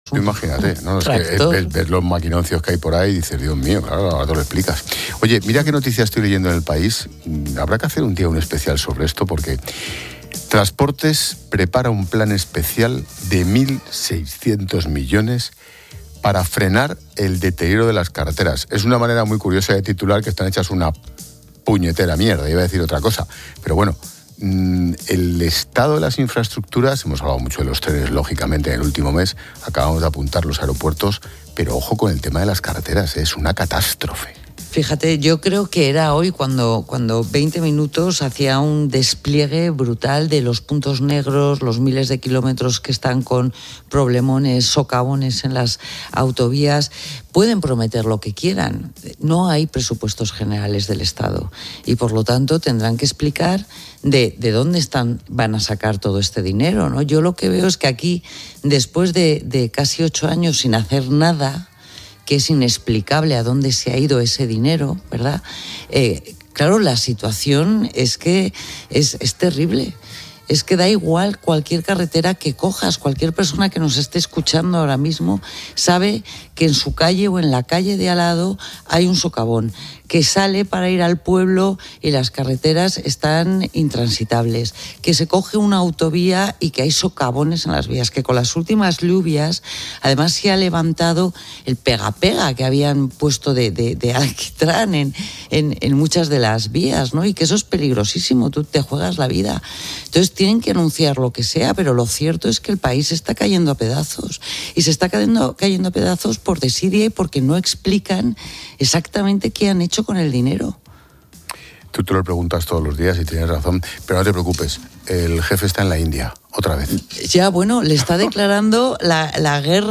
"Que eso es peligrosísimo, tú te juegas la vida", se ha comentado en antena, resumiendo el sentir de muchos conductores que enfrentan el riesgo a diario.